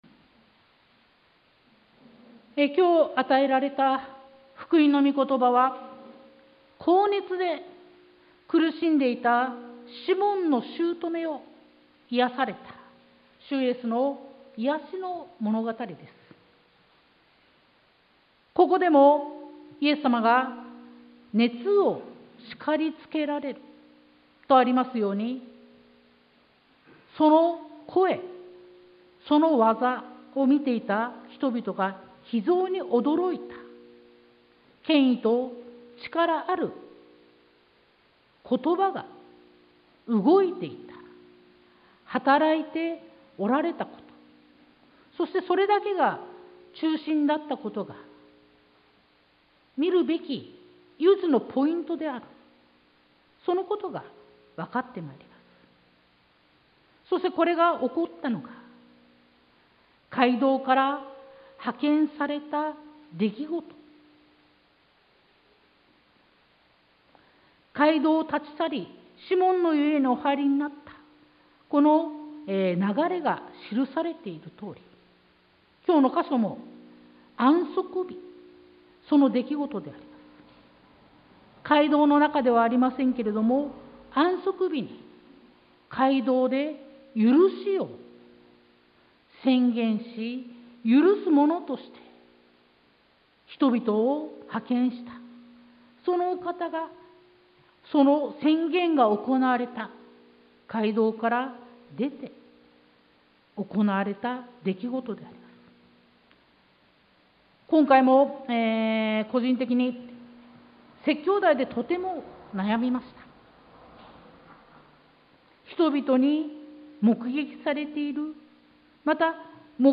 sermon-2022-06-19